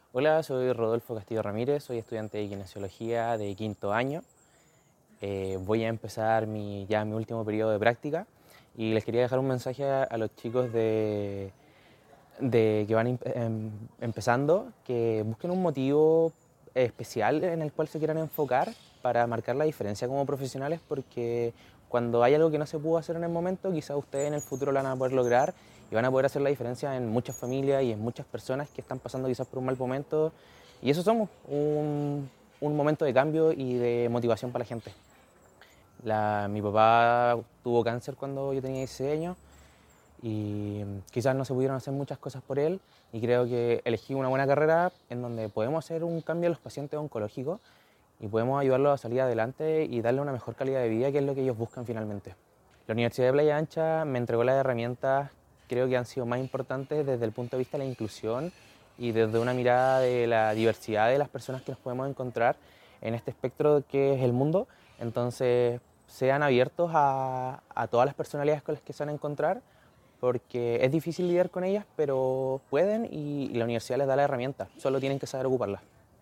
Compartimos con ustedes parte de lo que conversamos con algunos estudiantes, quienes desde su historia personal, evaluaron lo que significa para ellos, el inicio de sus prácticas profesionales, a fines de este mes.
Testimonios